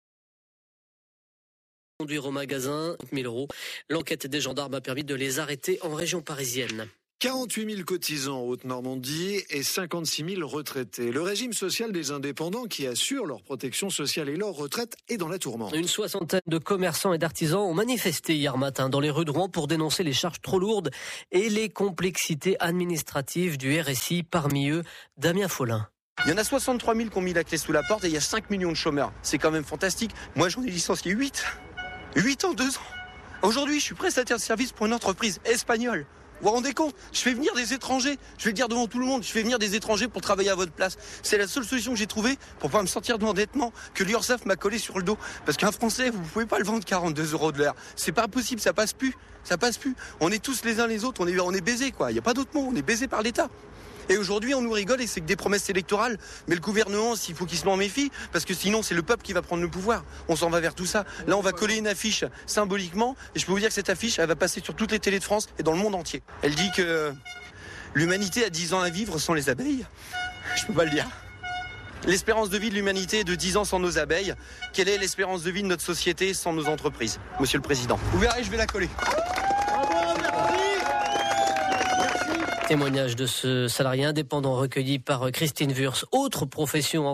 Le régime social des travailleurs indépendants (les "petits commerçants) est dans la tourmente. Un témoignage interessant sur France Bleu Haute Normandie.